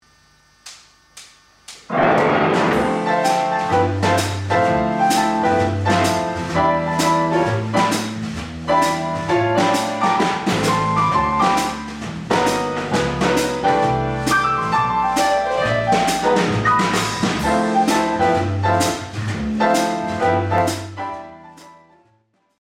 Hier finden Sie kurze MUSIK-DEMOS im MP3 Format.